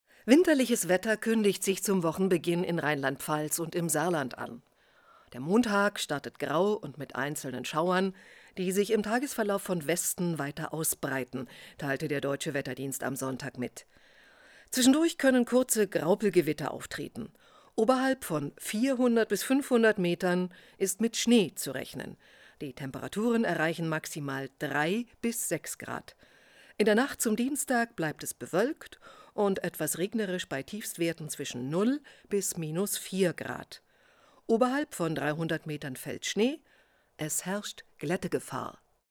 WettervorhersageRegionaler Sender
Nachrichten